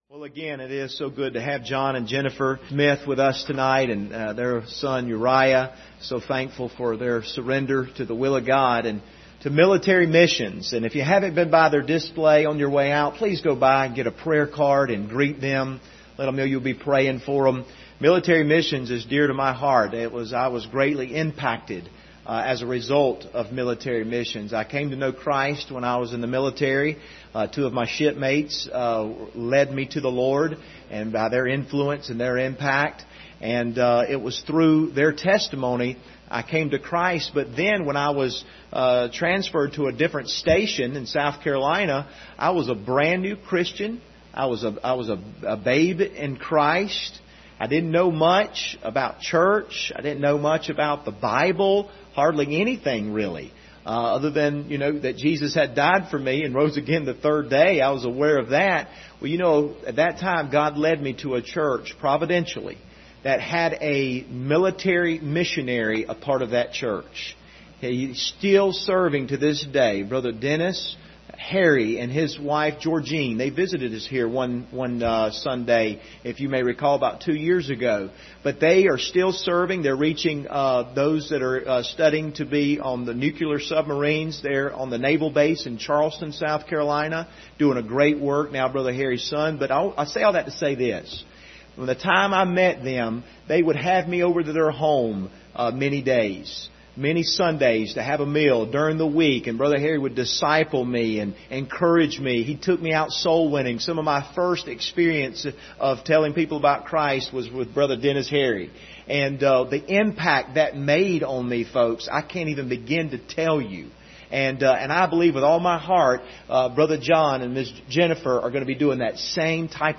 Passage: Mark 2:1-4 Service Type: Sunday Evening